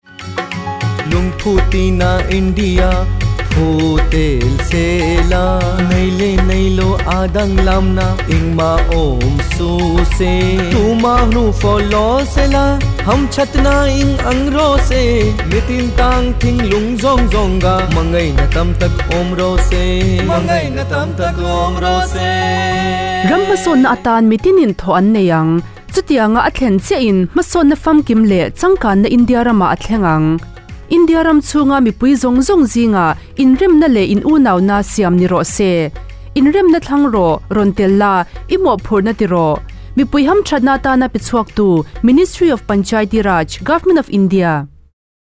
161 Fundamental Duty 5th Fundamental Duty Sprit of common brotherhood Radio Jingle Mizo